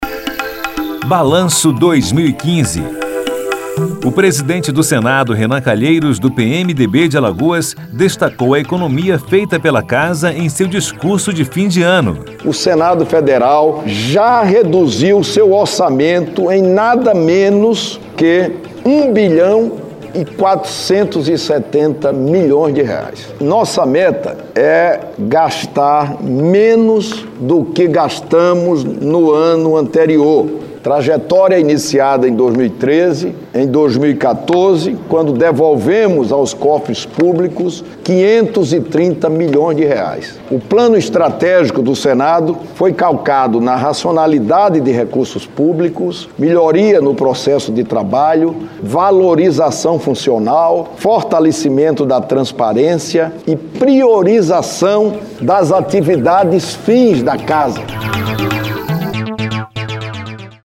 Em seu último discurso de 2015 no Plenário do Senado, ele lembrou os avanços obtidos durante o ano.